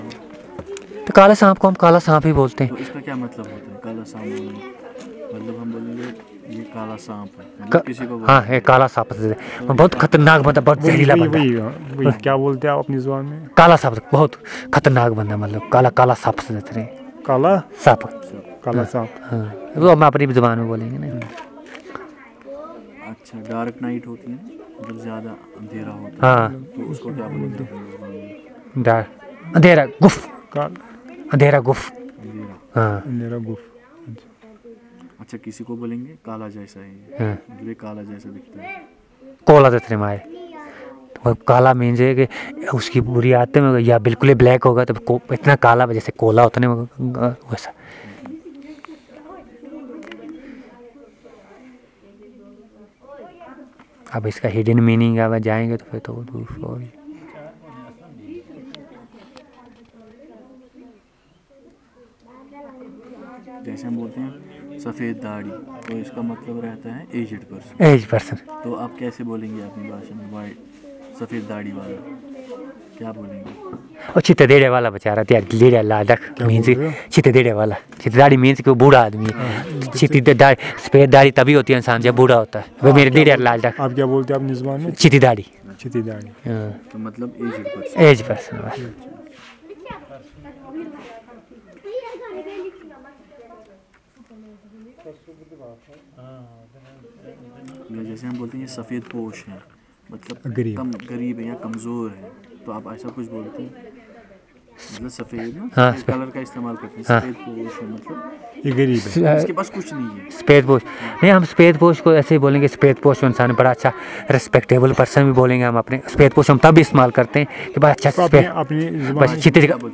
Elicitation of adjectives and colour terms.